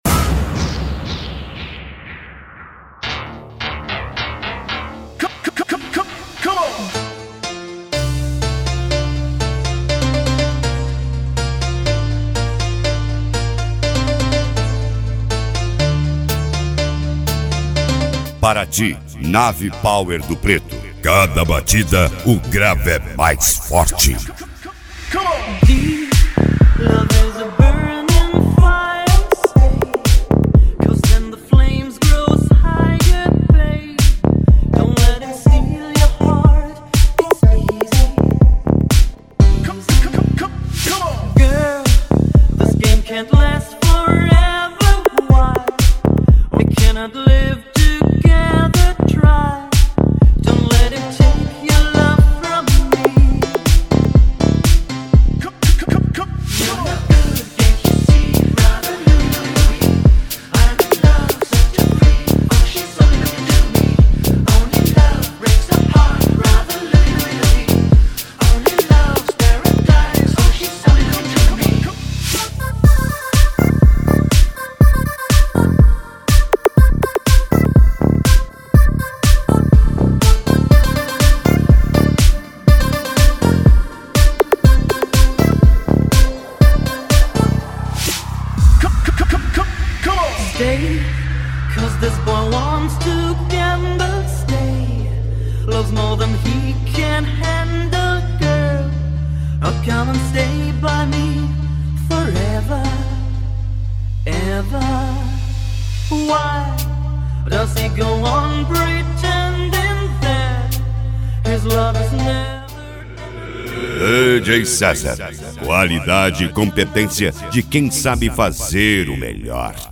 Mega Funk
SERTANEJO